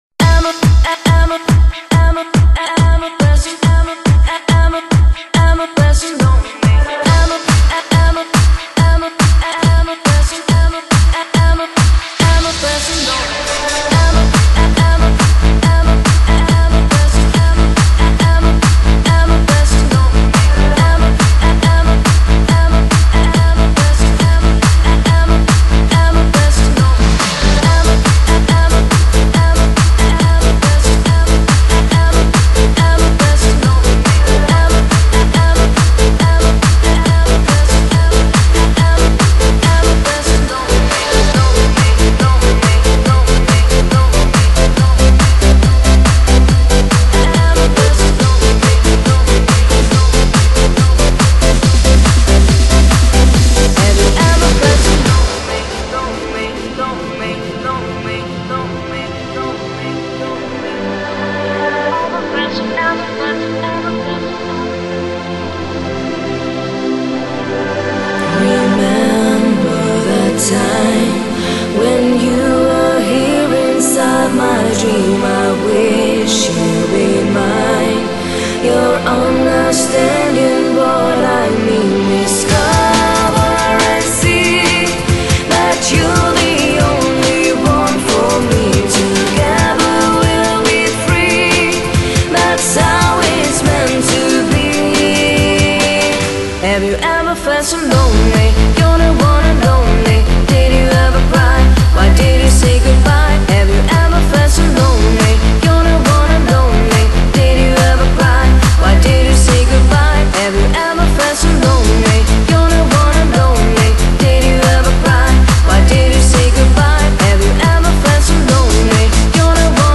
Genre: Dance, Pop | 21Tracks |